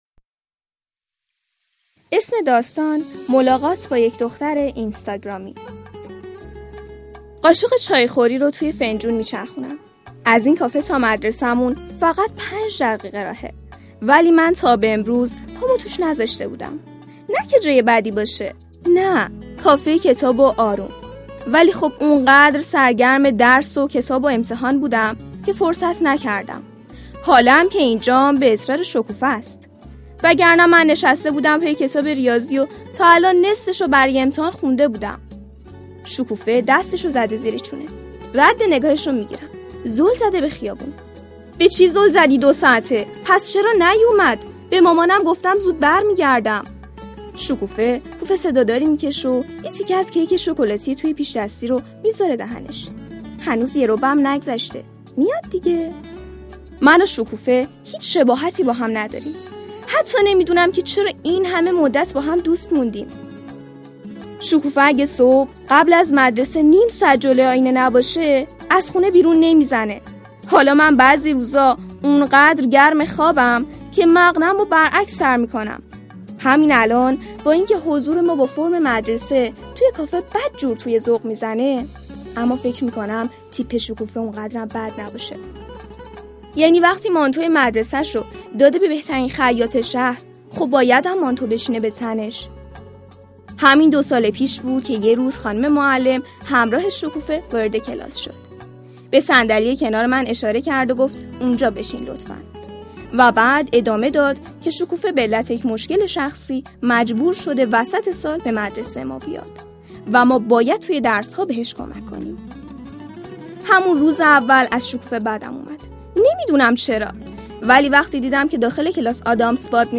■ کتاب صوتی؛ بی نمازها خوشبخت ترند [3:30:00]